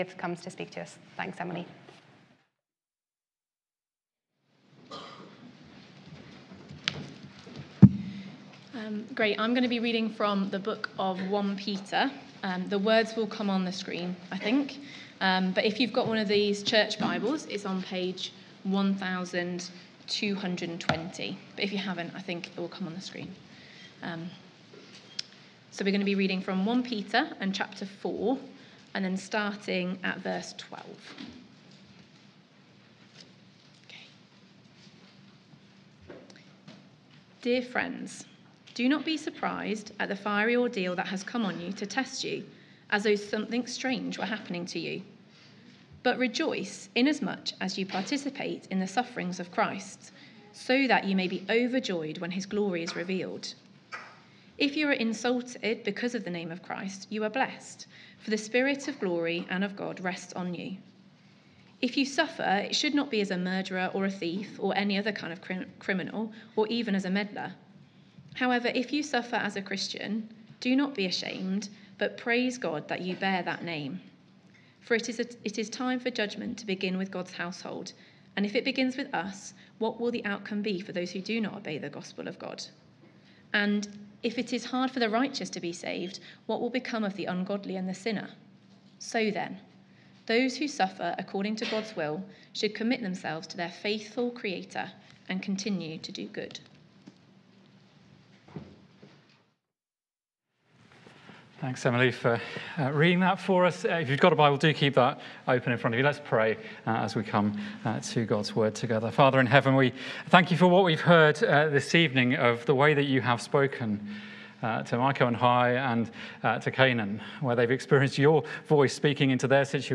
Baptism Service | Beeston Free Church